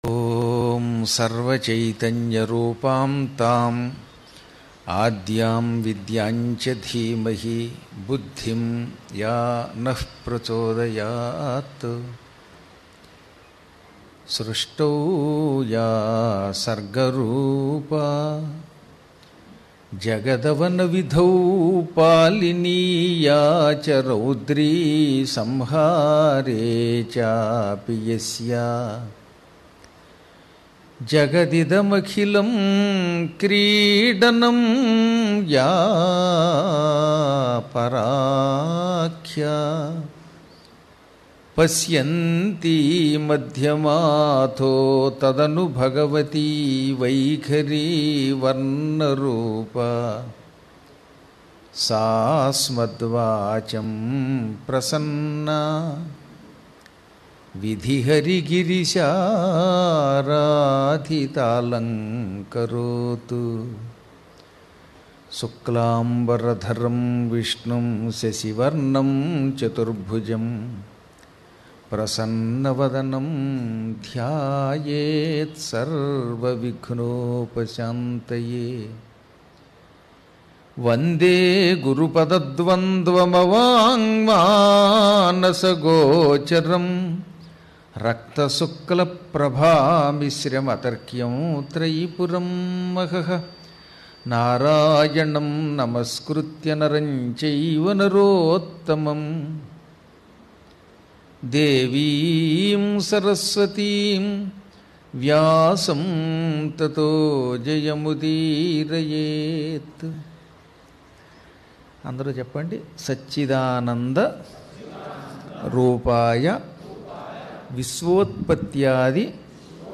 Day - 1/2 Srimadbhagavatam | Pravachanam
Live From Hyderabad 1